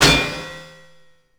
slam.wav